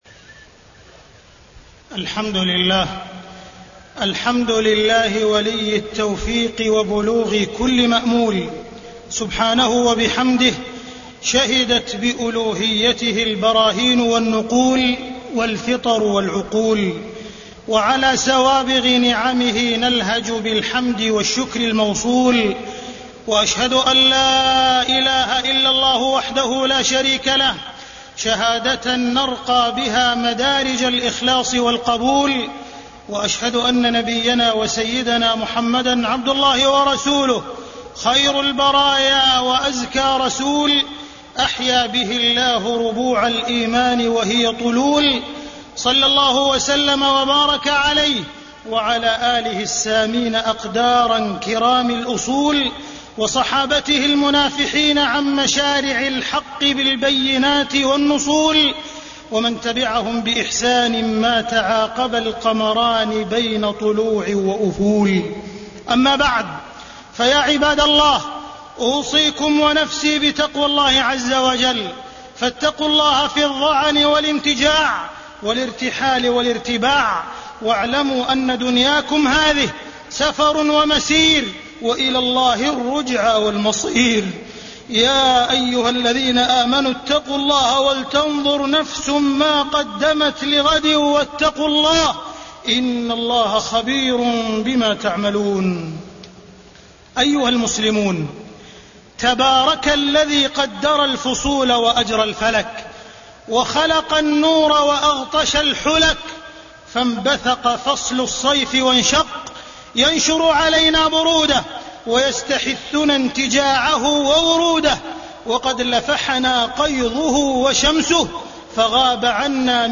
تاريخ النشر ٨ جمادى الآخرة ١٤٢٨ هـ المكان: المسجد الحرام الشيخ: معالي الشيخ أ.د. عبدالرحمن بن عبدالعزيز السديس معالي الشيخ أ.د. عبدالرحمن بن عبدالعزيز السديس فقه السياحة وبدع في رجب